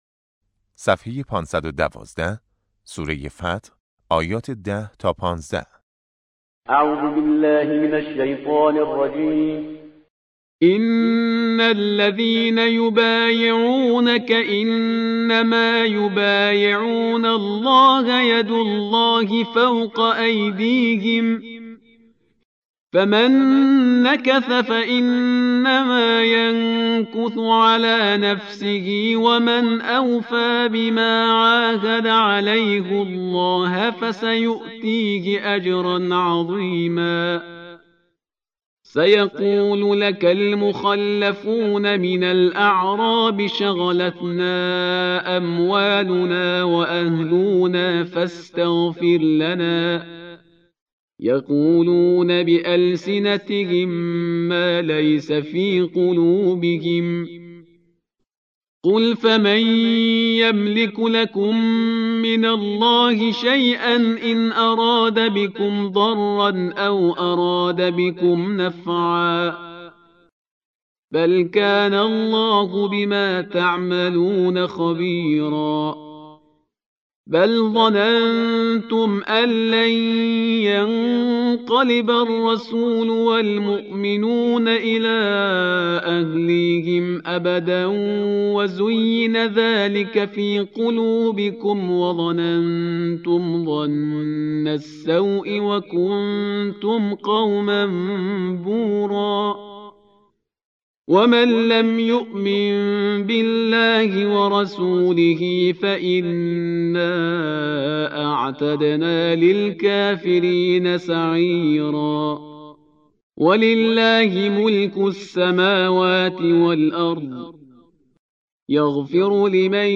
قرائت درس چهارم جلسه اول قرآن نهم